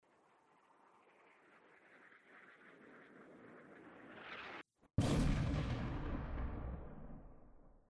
mortarimpactflame400m.mp3